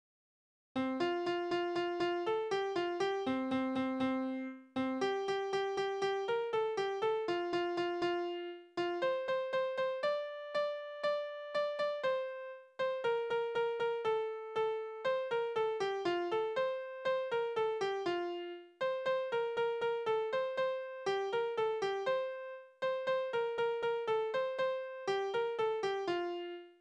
Tonart: F-Dur
Taktart: 4/8